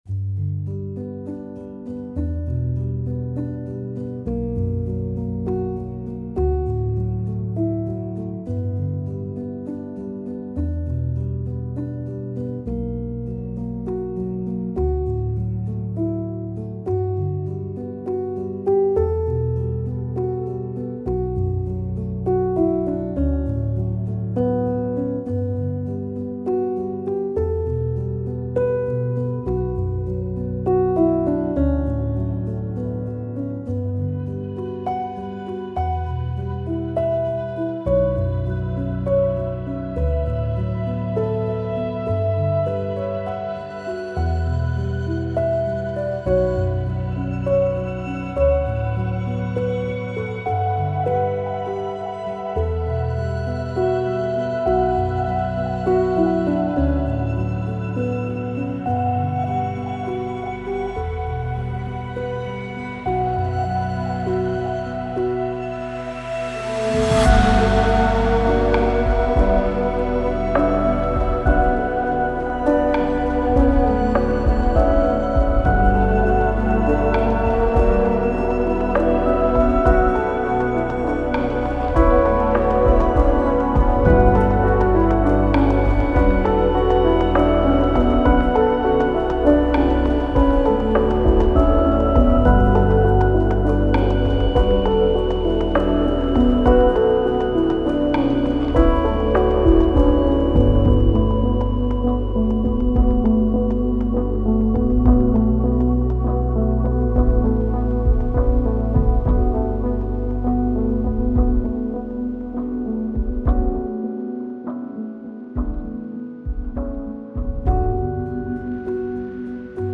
Remote solitary melodies, skim vast emotional landscapes.